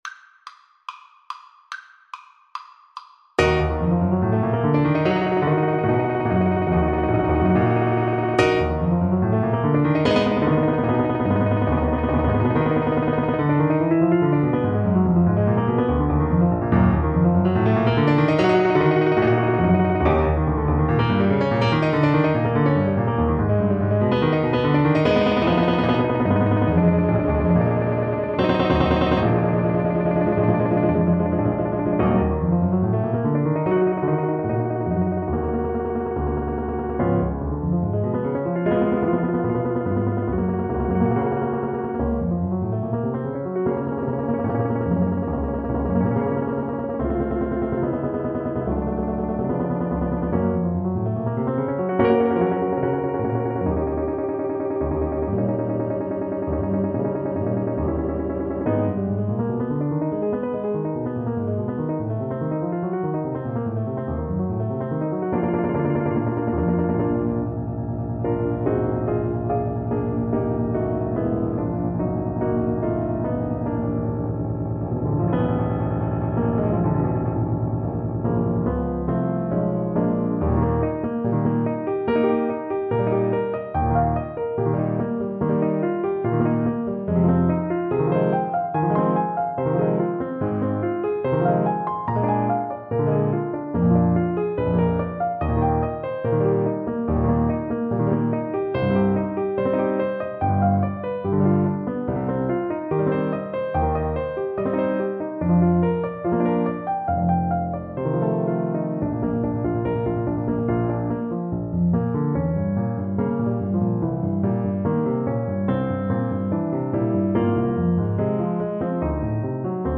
Free Sheet music for Tenor Voice
Play (or use space bar on your keyboard) Pause Music Playalong - Piano Accompaniment Playalong Band Accompaniment not yet available transpose reset tempo print settings full screen
Eb minor (Sounding Pitch) (View more Eb minor Music for Tenor Voice )
Allegro moderato (=144) (View more music marked Allegro)
4/4 (View more 4/4 Music)
Classical (View more Classical Tenor Voice Music)